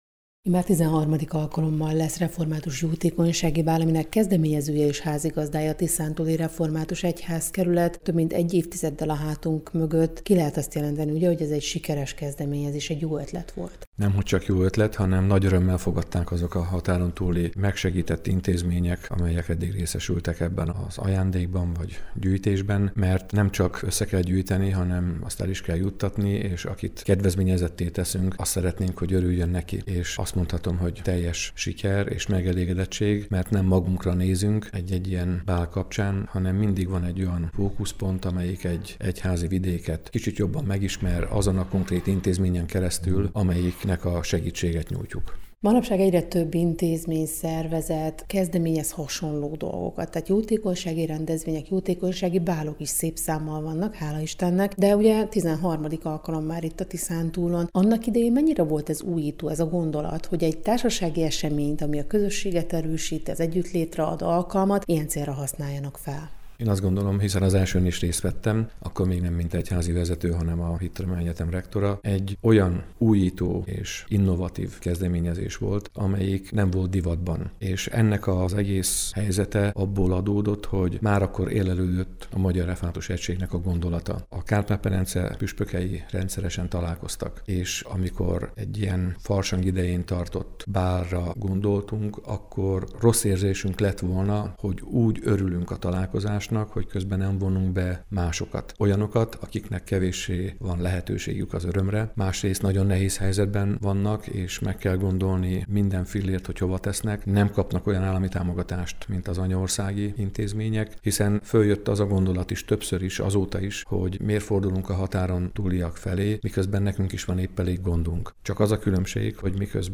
interjút az Európa Rádióban a Tiszántúli Református Jótékonysági Bál történetéről, szervezéséről és fontosságáról: